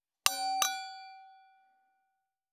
314金属製のワインカップ,ステンレスタンブラー,シャンパングラス,ウィスキーグラス,ヴィンテージ,ステンレス,金物グラス,
効果音厨房/台所/レストラン/kitchen食器
効果音